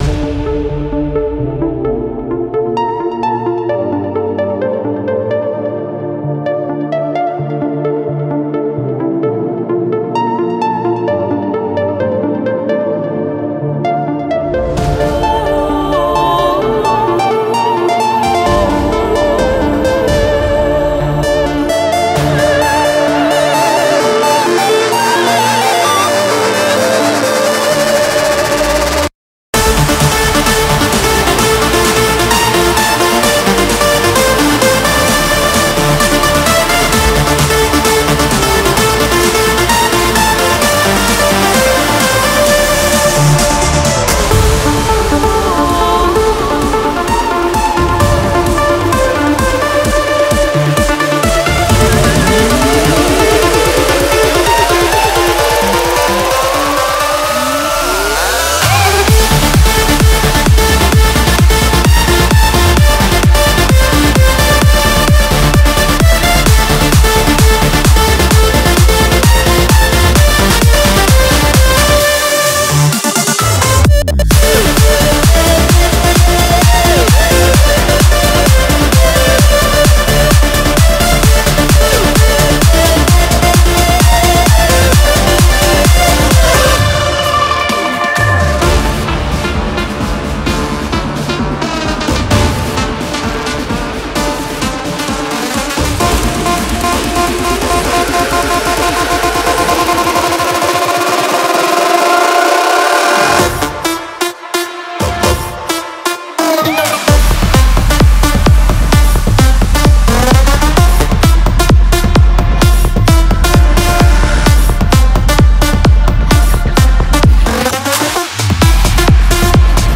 BPM65-130
Audio QualityMusic Cut